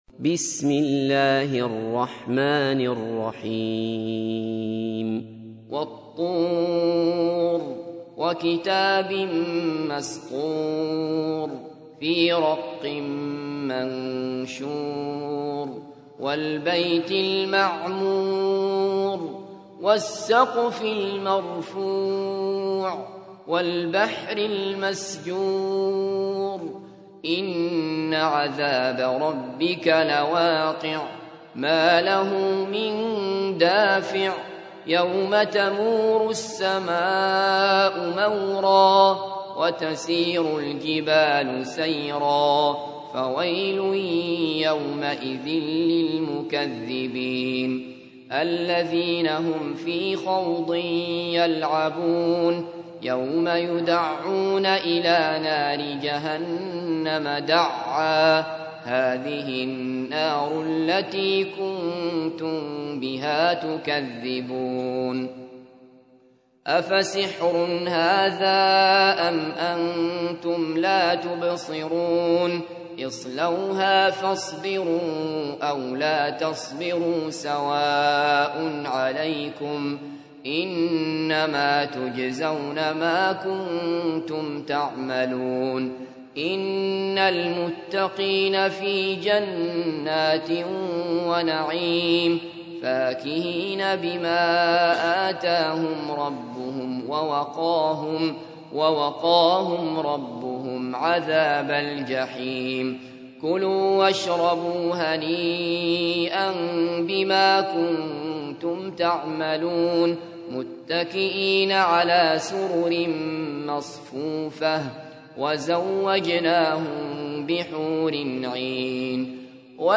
تحميل : 52. سورة الطور / القارئ عبد الله بصفر / القرآن الكريم / موقع يا حسين